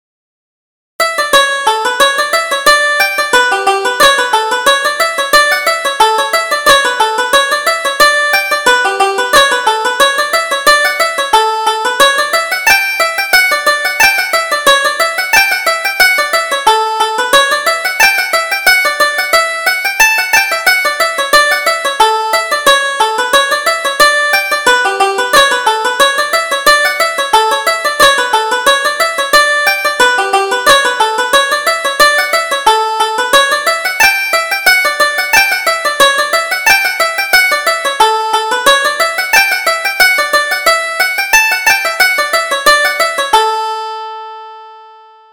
Reel: The Black Haired Lass